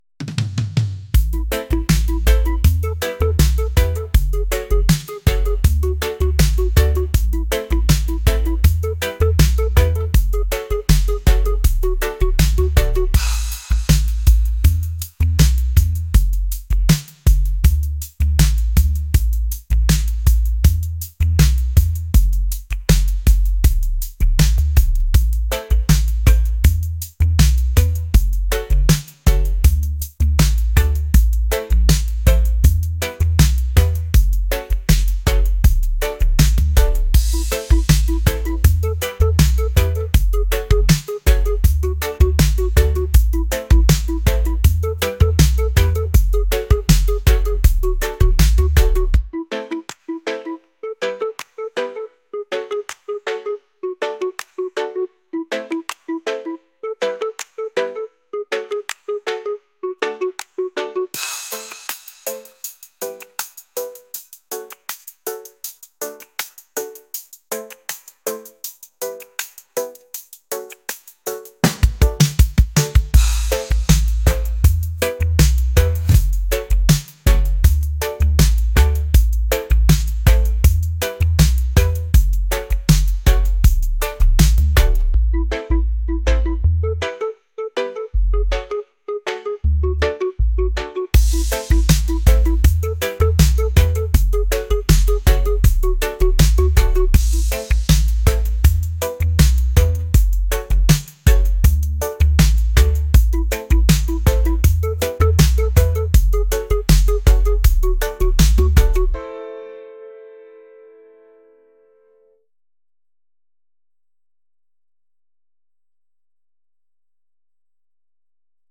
reggae | lounge | folk